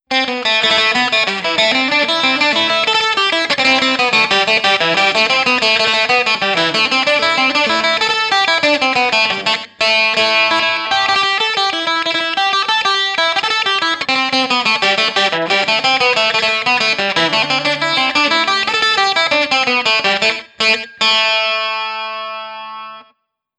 • irish bouzouki folk amp sequence.wav
irish_bouzouki_folk_amp_sequence_vCB.wav